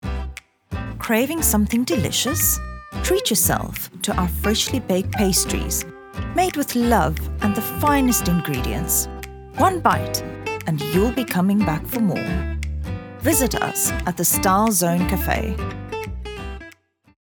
authoritative, dramatic, elegant, soothing
Elegant, Sophisticated, and sometimes, just a little bit silly.
Style Zone Cafe Soft Sell VO